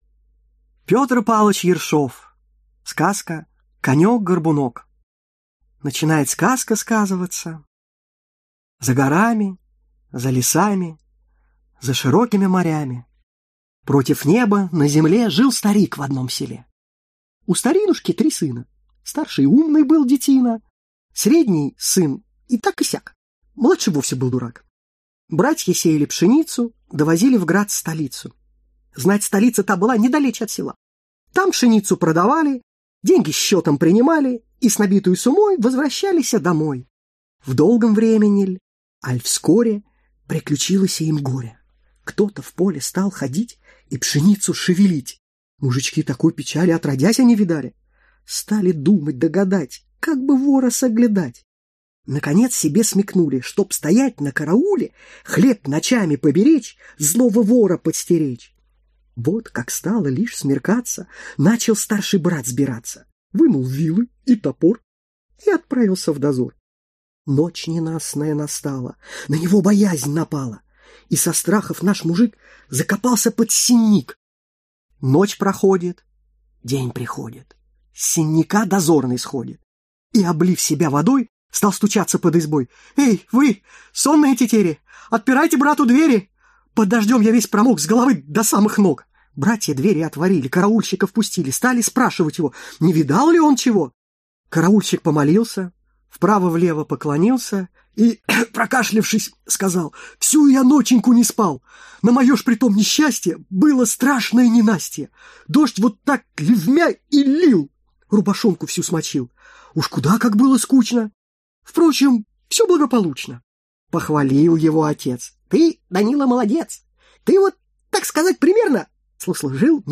Аудиокнига Конек-горбунок | Библиотека аудиокниг